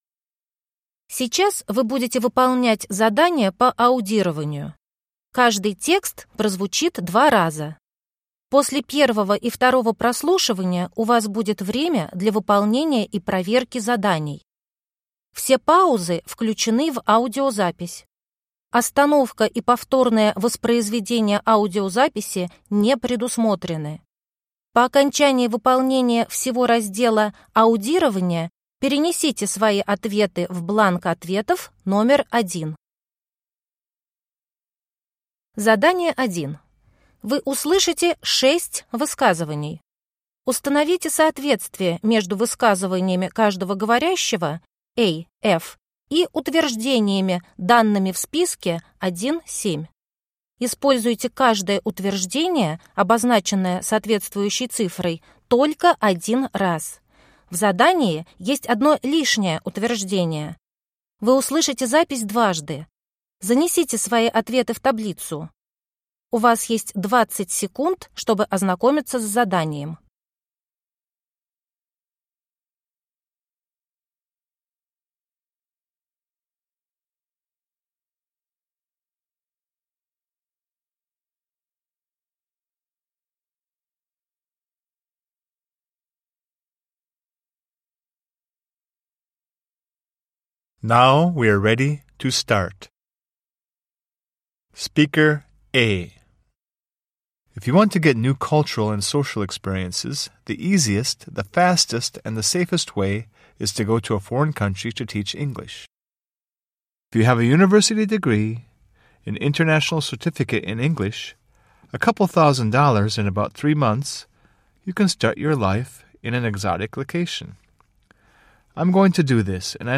Вы услышите 6 высказываний.